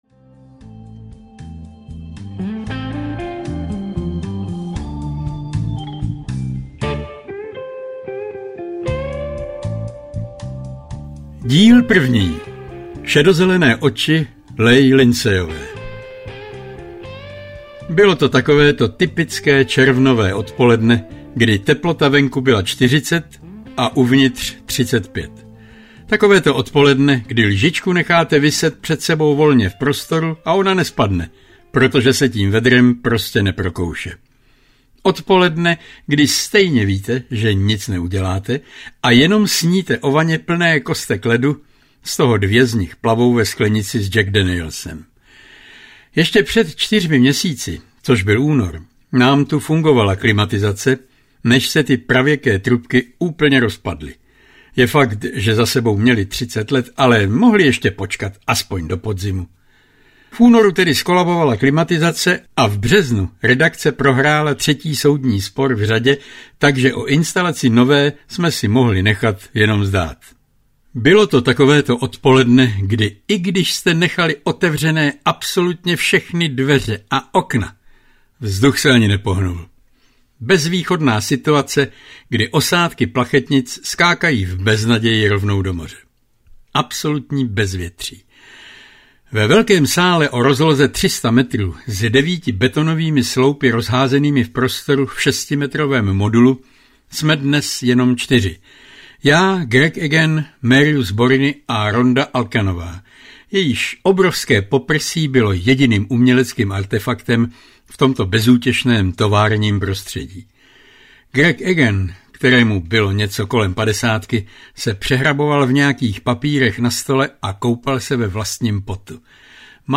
Šest diamantů audiokniha
Ukázka z knihy